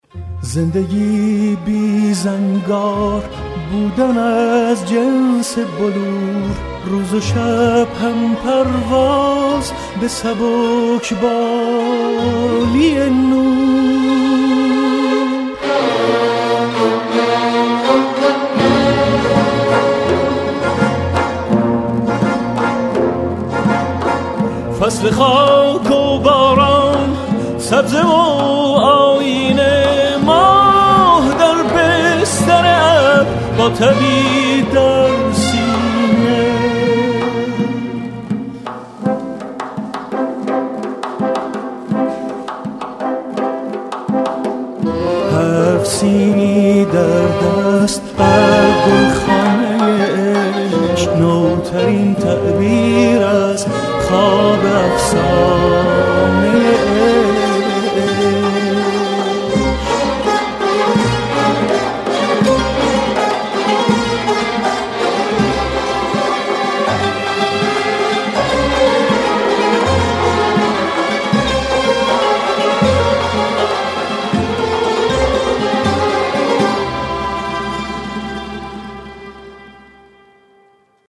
آهنگ موبایل باکلام سنتی